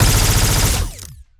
Added more sound effects.
GUNAuto_Plasmid Machinegun B Burst_03_SFRMS_SCIWPNS.wav